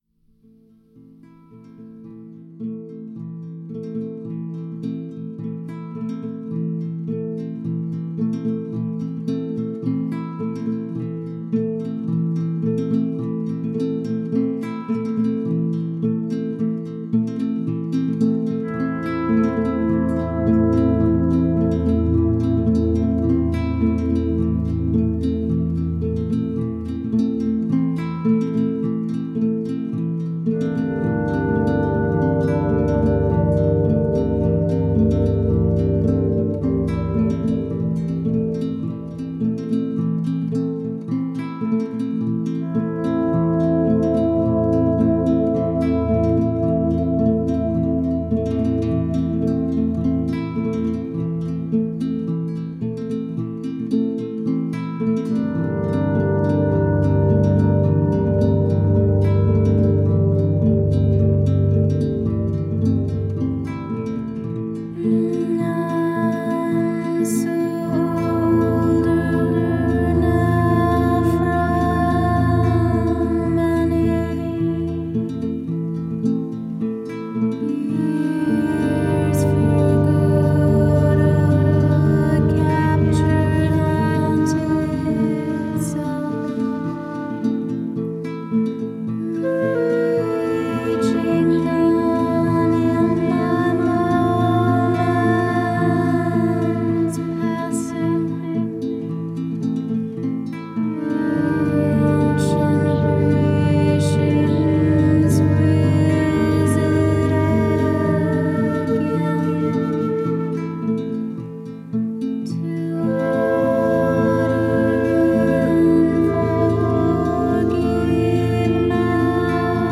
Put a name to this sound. Genre: Psych.